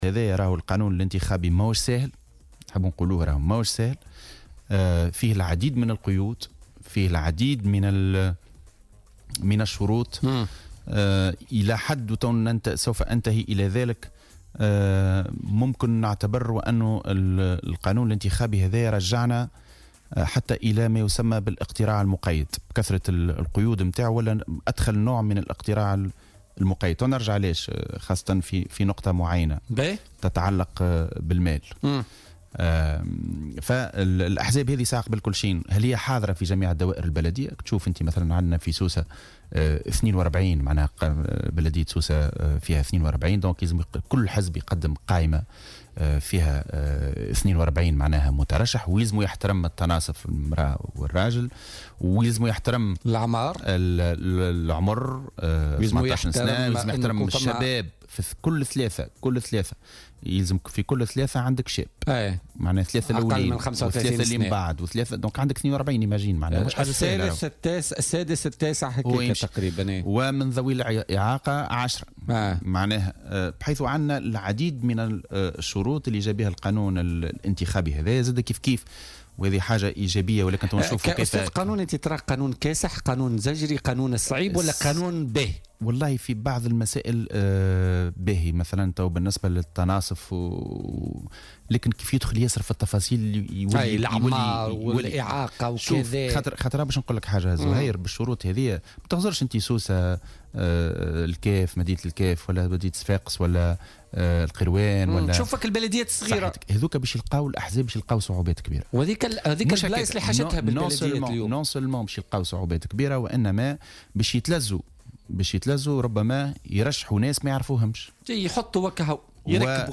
وأوضح في مداخلة له اليوم في برنامج "بوليتيكا" أن الأحزاب السياسية ستجد صعوبة نظرا لهذه الشروط، منتقدا عودة ما أسماه "الاقتراع المقيّد" والذي اعتبره مخالفا للدستور، وفق تعبيره.